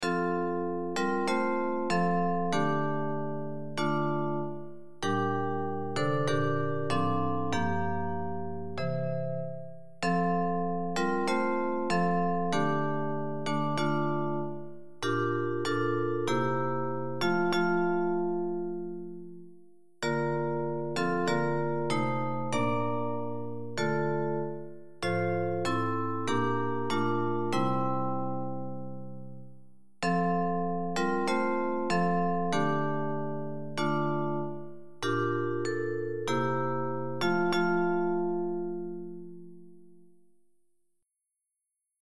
public domain Christmas hymns